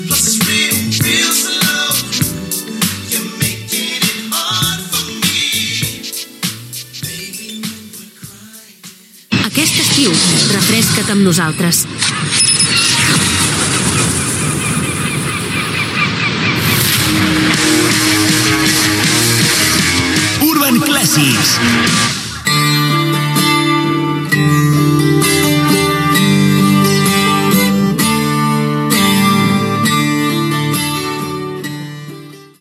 Indicatiu del canal i tema musical